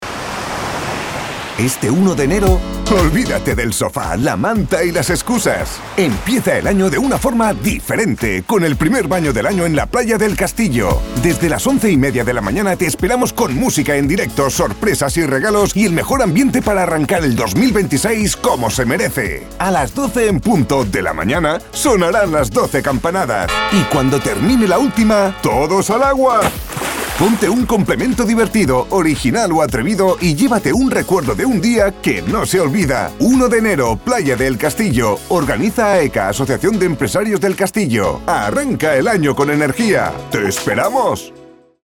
Demo Cuña Clientes - Radio Insular